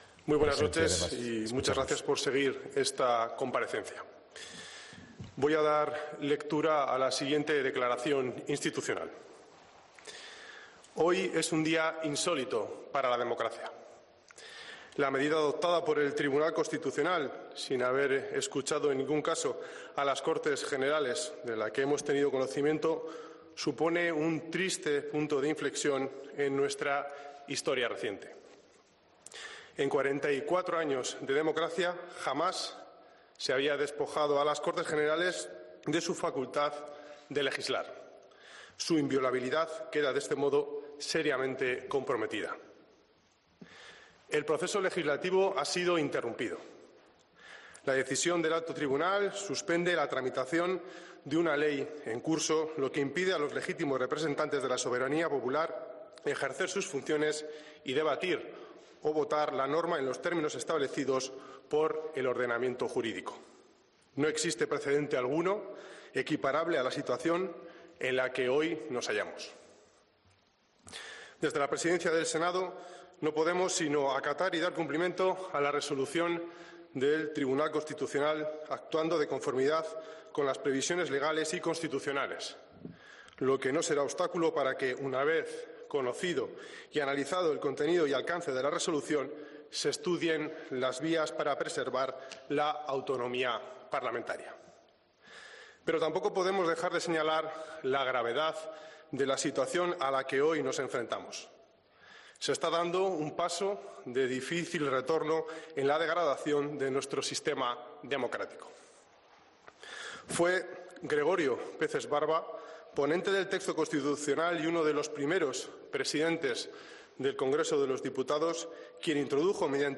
Las palabras del presidente del Senado, Ander Gil, tras la decisión del Constitucional